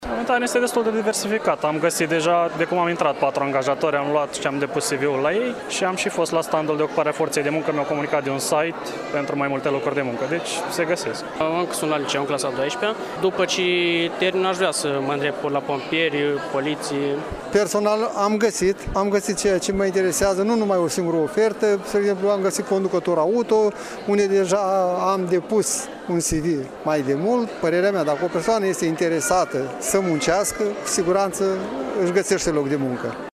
19-oct-rdj-17-vox-pop-bursa-Iasi.mp3